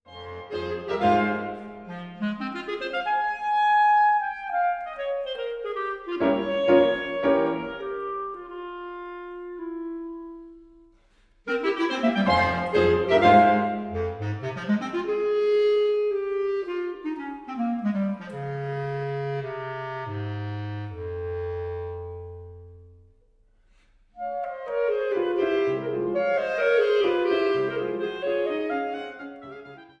Klarinette
Bassetthorn und Klarinette
Klavier